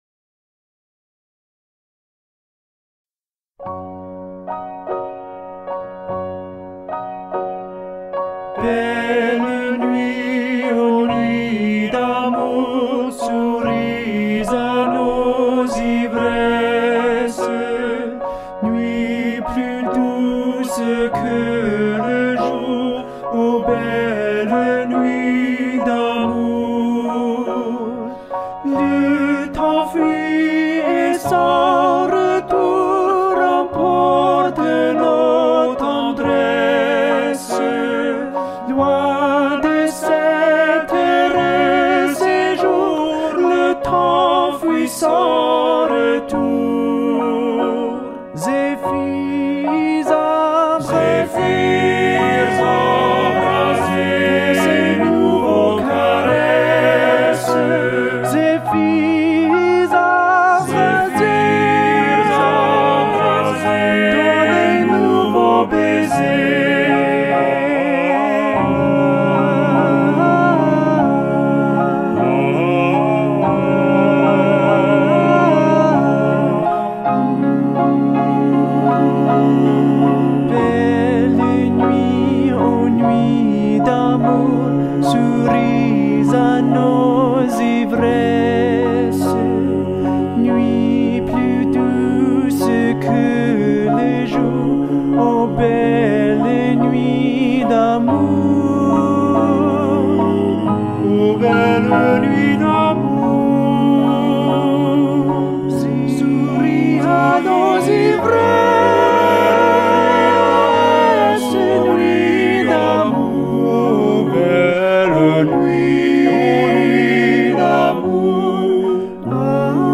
R�p�tition de la pi�ce musicale N� 681
Toutes voix Barcarolle (Offenbach, Contes d'Hoffmann) - 5 voices and piano.mp3